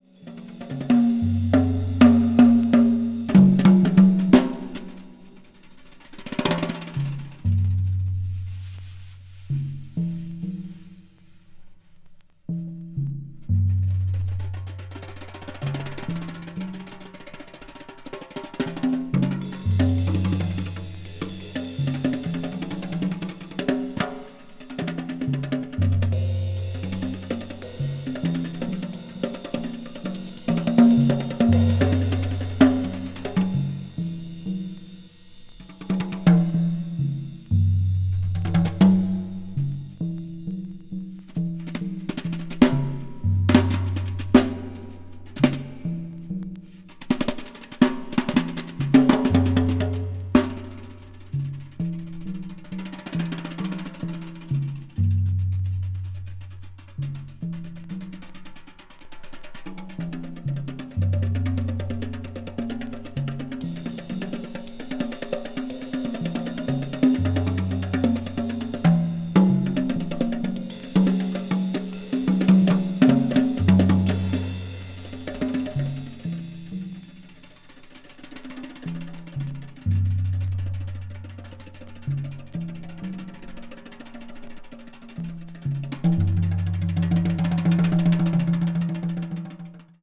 (わずかにチリノイズが入る箇所あり)